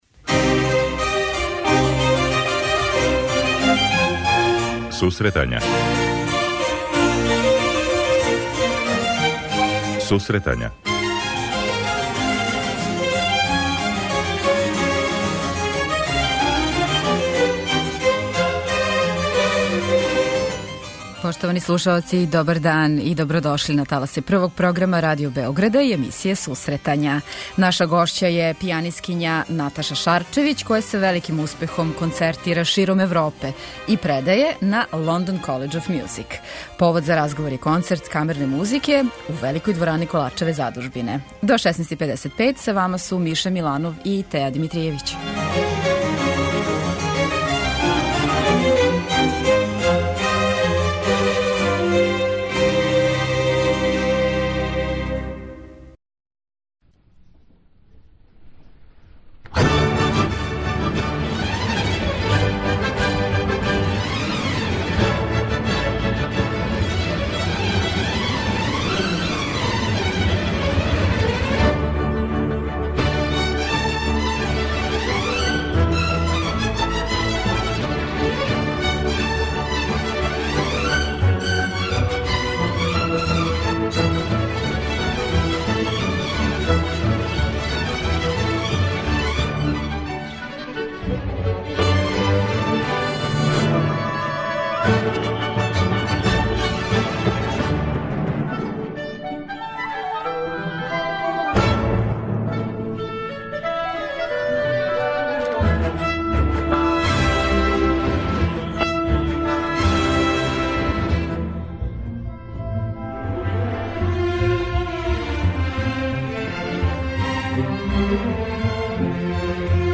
Емисија за оне који воле уметничку музику.